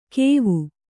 ♪ kēvu